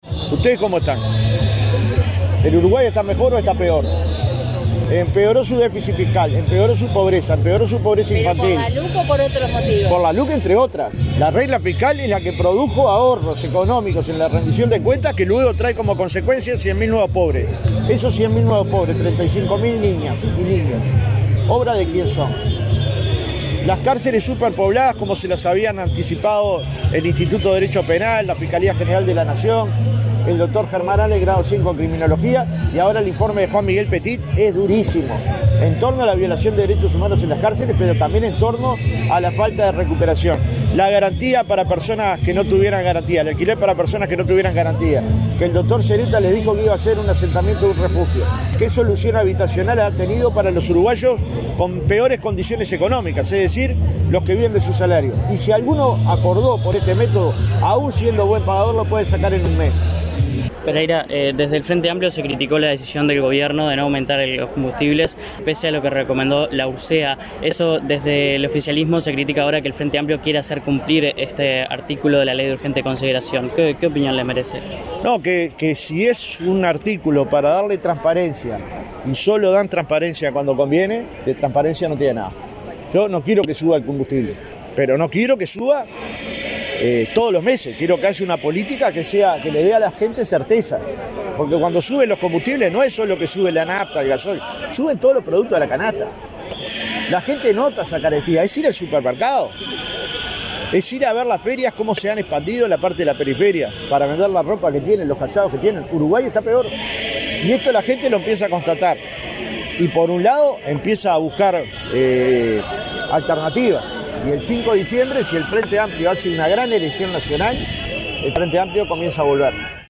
«No quiero que suban, pero no quiero que suban todos los meses», expresó, en rueda de prensa.